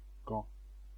kiejtése: ɡɑ̃); régiesen Gaunt) város Belgium Flandria régiójában, a Leie folyó két partján; Kelet-Flandria tartomány fővárosa és legnagyobb települése, valamint Brüsszel és Antwerpen után az ország harmadik legnagyobb területű városa.[3] Kikötő- és egyetemváros.